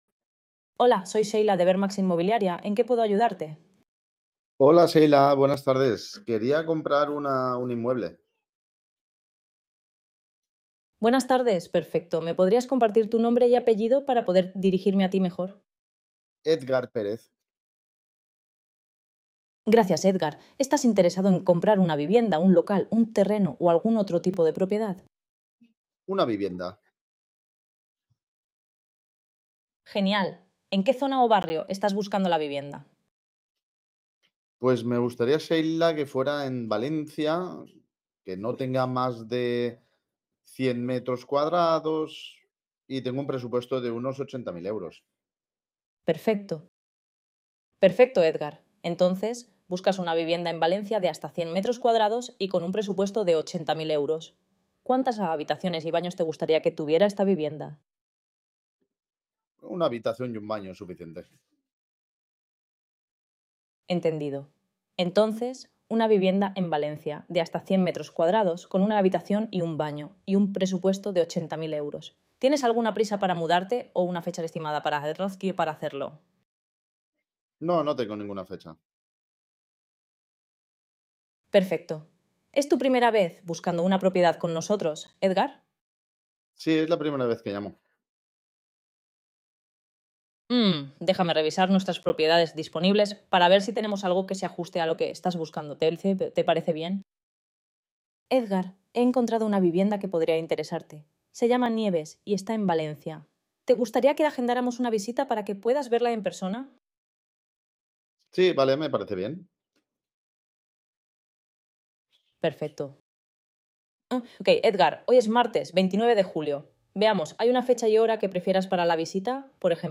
Con nuestra solución de agente inteligente automatizado, podrás atender tanto llamadas como chats en cualquier momento del día, sin necesidad de personal humano y sin perder ni un solo contacto.
AUDIO EJEMPLO DE LLAMADA 3:08 min